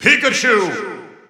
The announcer saying Pikachu's name in English and Japanese releases of Super Smash Bros. 4 and Super Smash Bros. Ultimate.
Pikachu_English_Announcer_SSB4-SSBU.wav